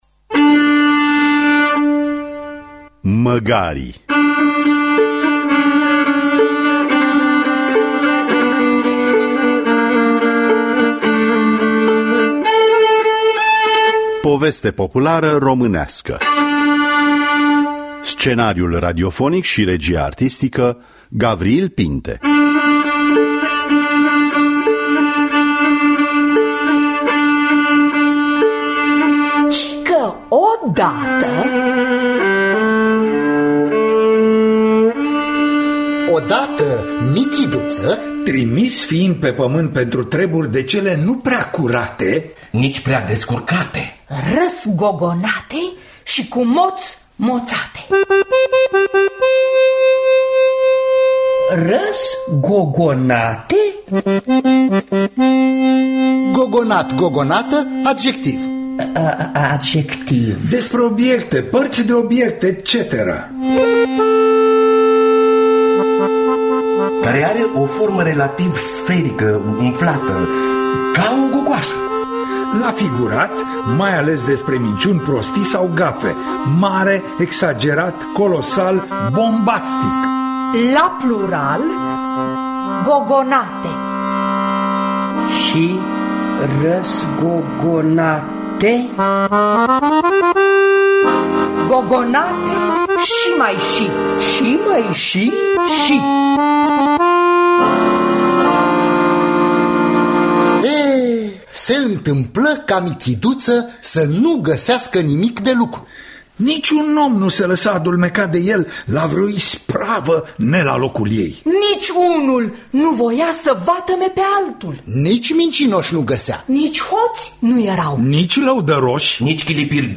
Scenariul radiofonic şi regia artistică